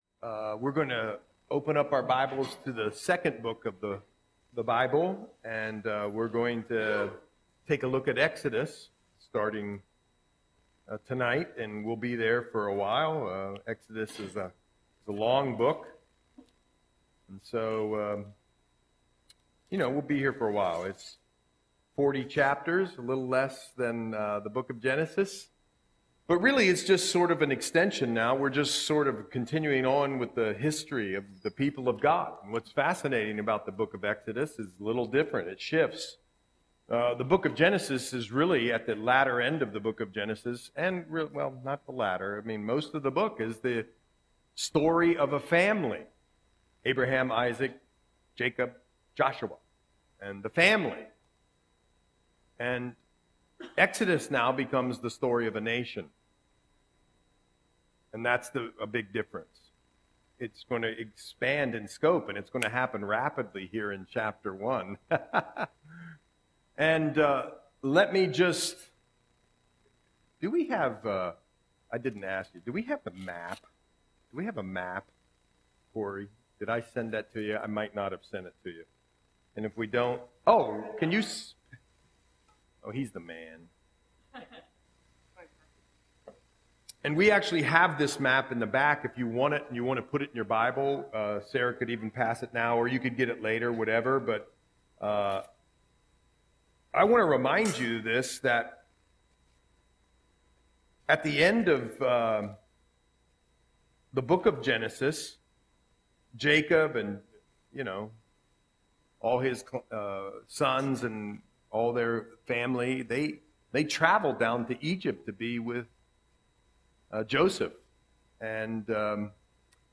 Audio Sermon - October 9, 2024